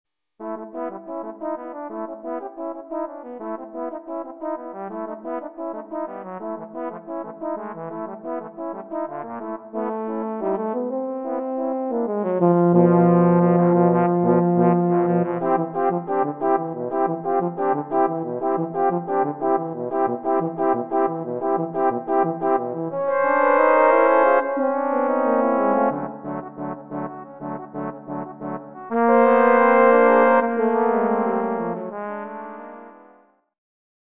【編成】トロンボーン八重奏（6 Tenor Trombone, 2 Bass Trombone）
全曲変拍子で各パートとも音域が広いです。